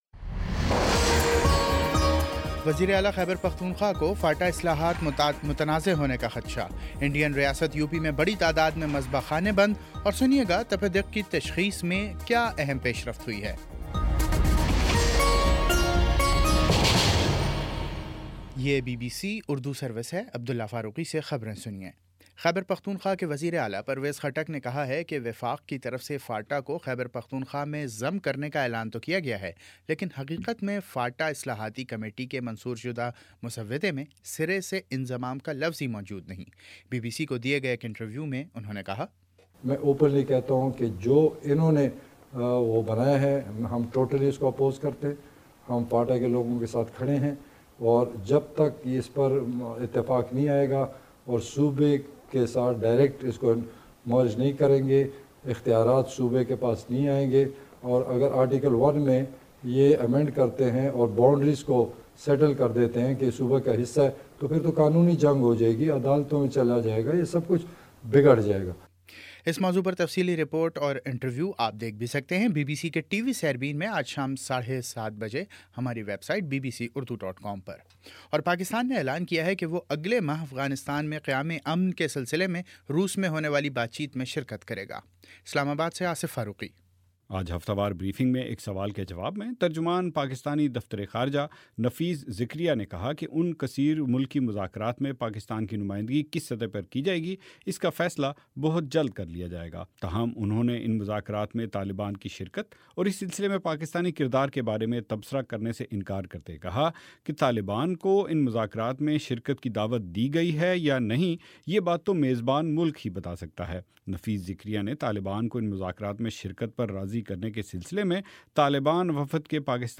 مارچ 24 : شام چھ بجے کا نیوز بُلیٹن